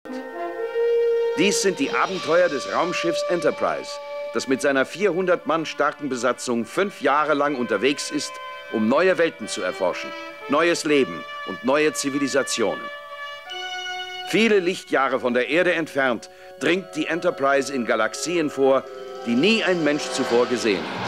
spricht den Vorspann von Raumschiff Enterprise (Sat.1-Fassung)